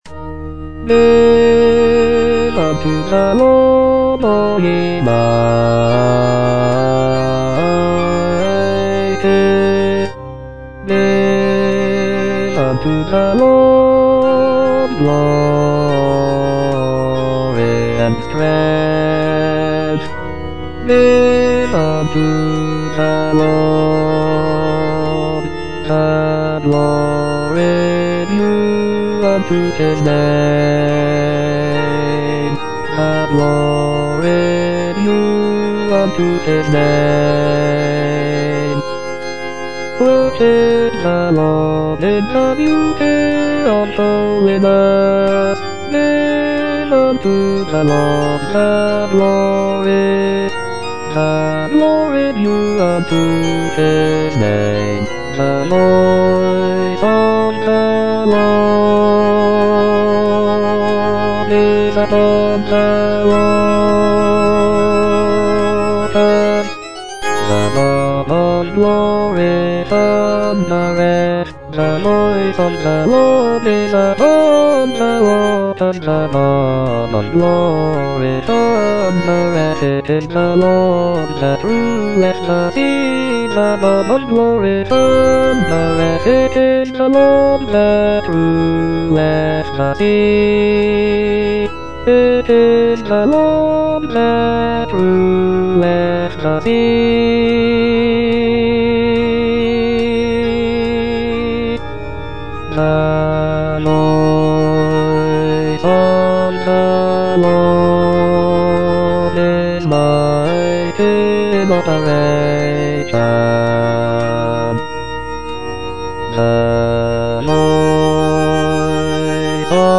E. ELGAR - GIVE UNTO THE LORD Bass I (Voice with metronome) Ads stop: auto-stop